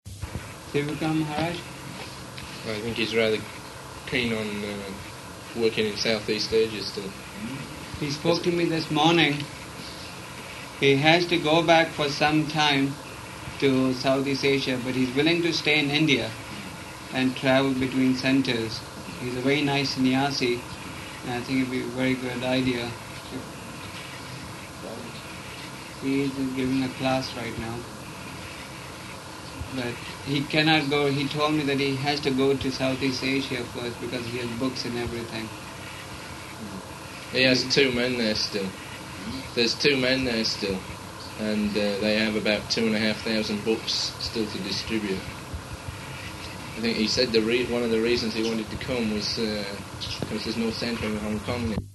Room Conversation
Location: Bombay